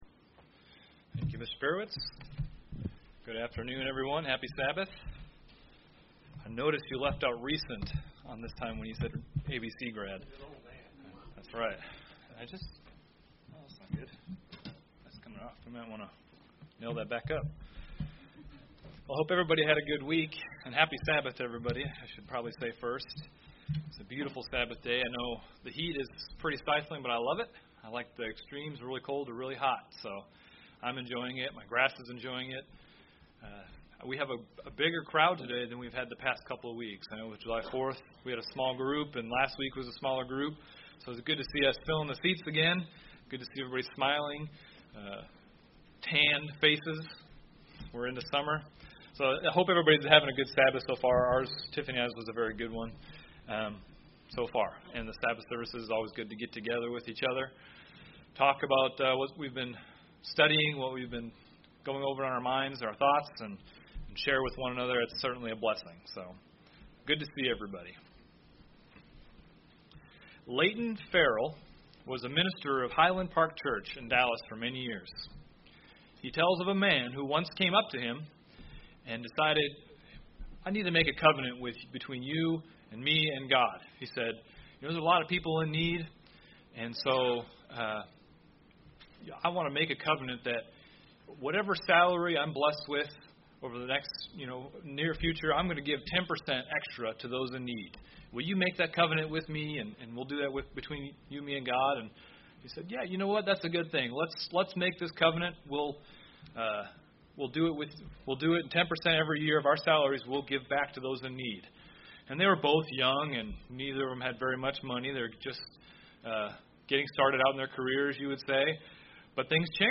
In this first sermon of a multi-part sermon series, the spearker looks into the topic of covenants throughout the bible. In this first part, the spearker gives an overview, an introduction, into covenants.